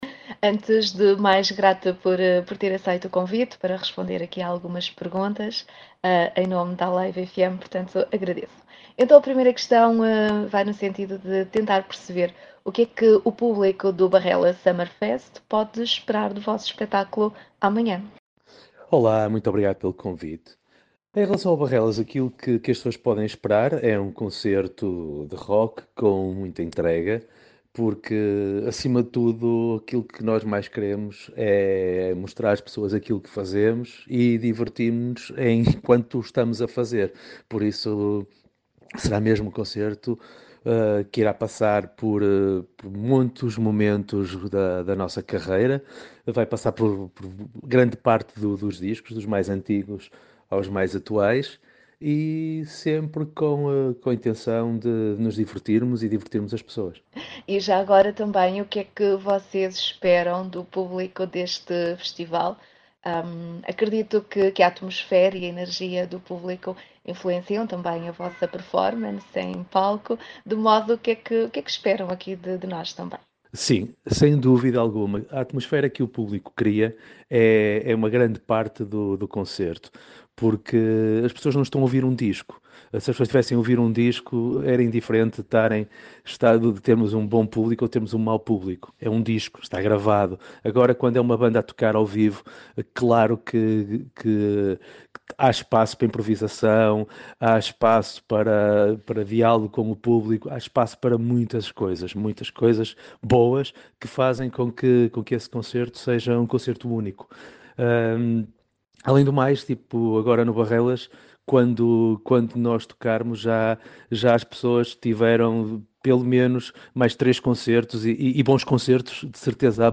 Os Blind Zero estiveram em entrevista à Alive FM
Entrevista-Blind-Zero.mp3